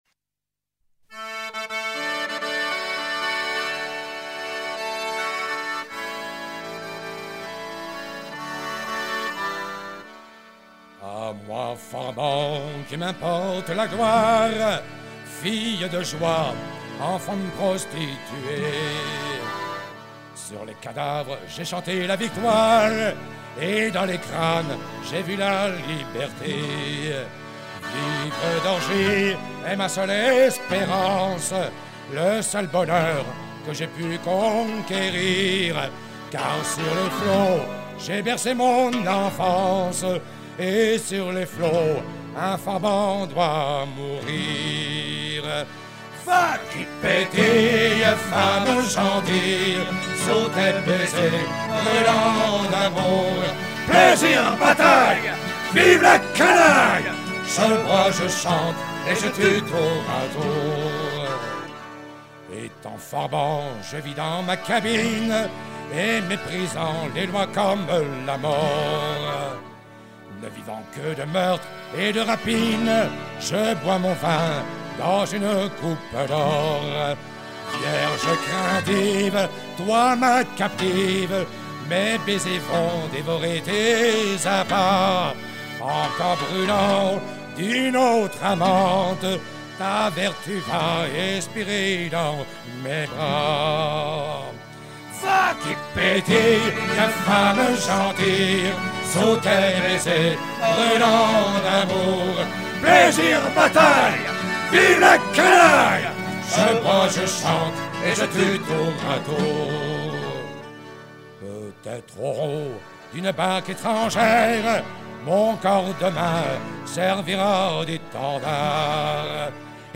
Genre strophique
Chansons de ports
Pièce musicale éditée